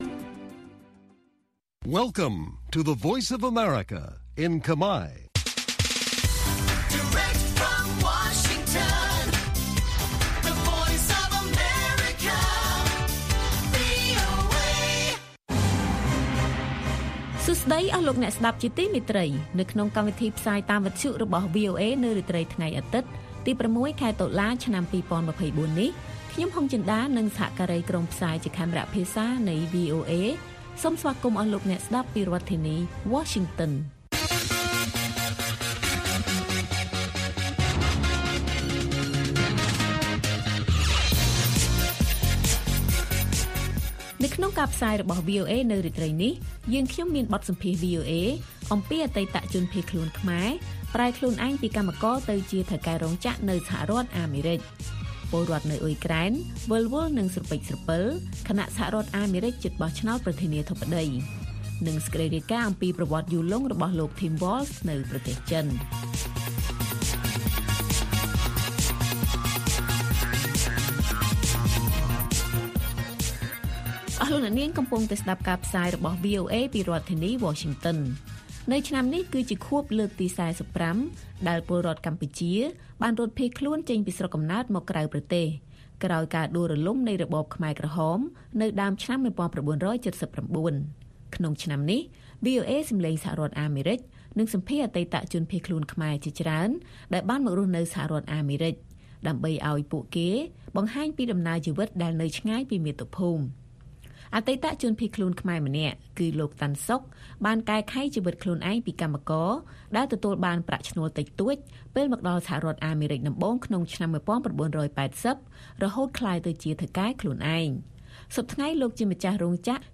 ព័ត៌មានពេលរាត្រី ៦ តុលា៖ បទសម្ភាសន៍ VOA អំពីអតីតជនភៀសខ្លួនខ្មែរប្រែខ្លួនឯងពីកម្មករទៅជាថៅកែរោងចក្រនៅអាមេរិក